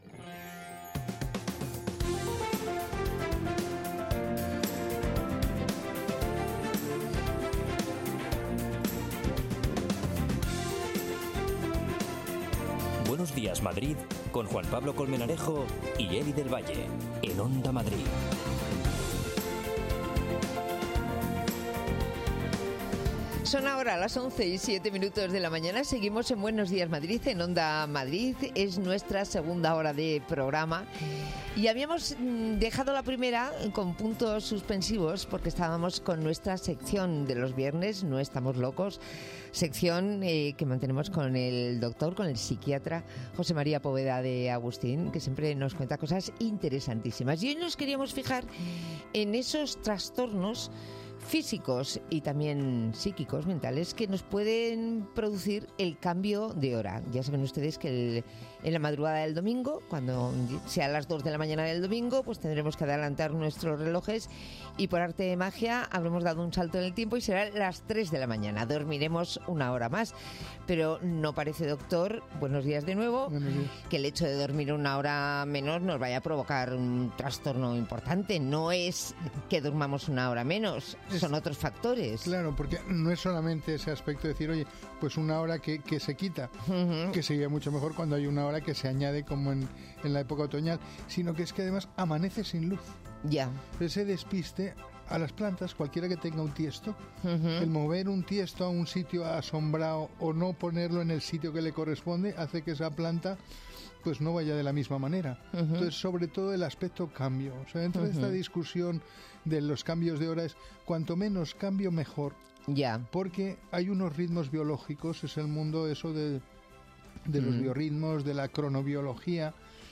Nuestra pregunta en la calle: ¿Se iría usted a vivir a un pueblo pequeño?